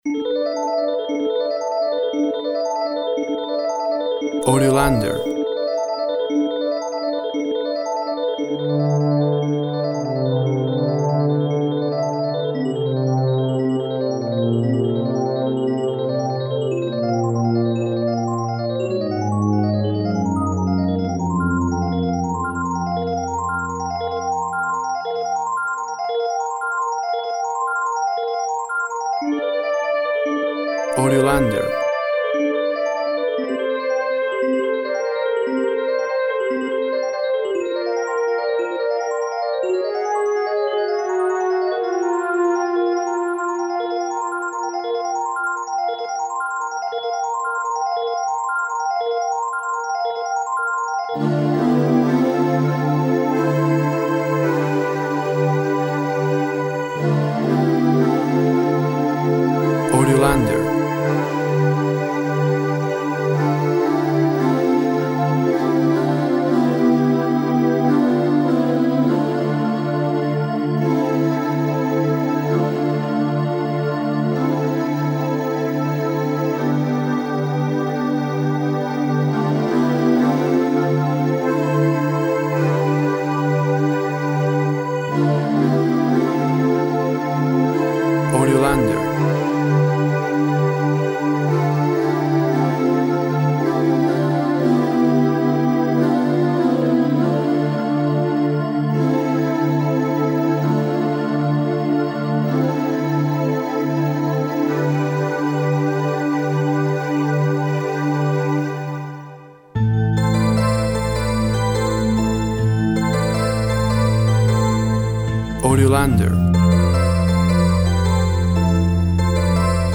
Tempo (BPM) 120/60